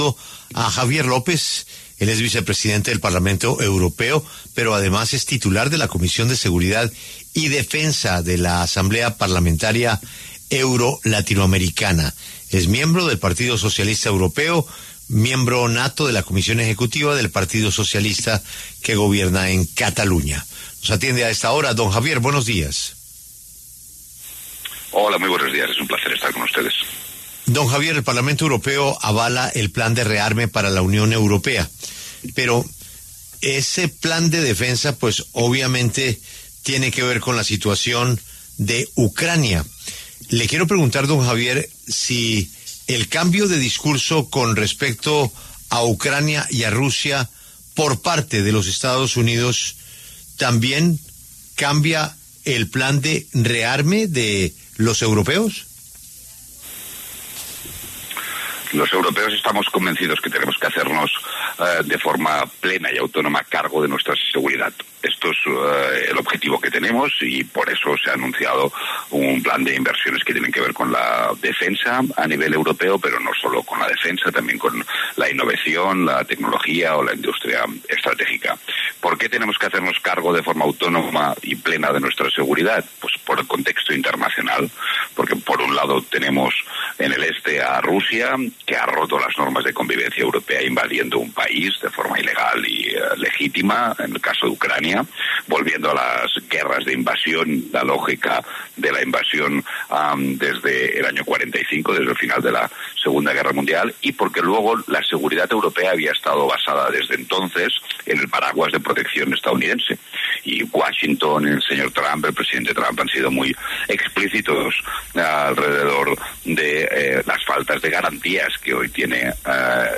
Javier López, político español y vicepresidente del Parlamento Europeo, aclaró en La W de qué se trata el plan de rearme que se propone para la Unión Europea y explicó algunos de los puntos clave.